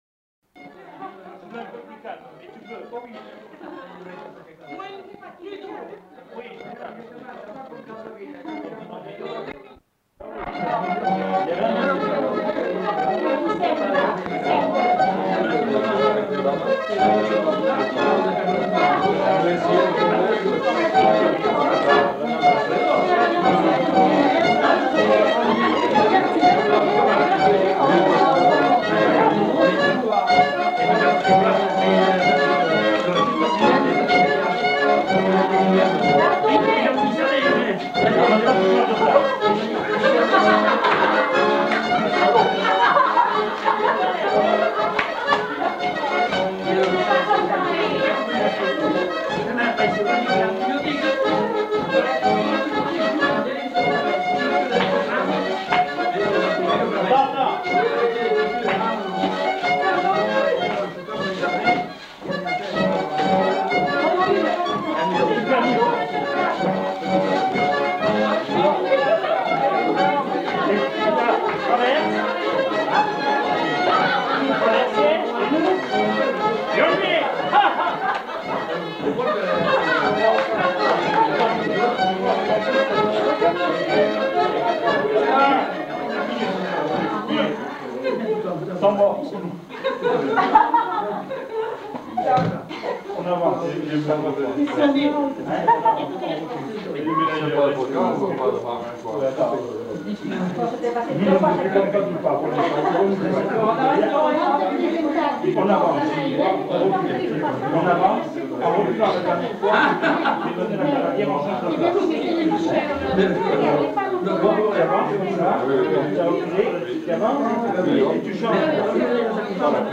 Genre : morceau instrumental
Instrument de musique : violon ; accordéon diatonique Danse : congo
Avec commentaires pour apprentissage de la danse.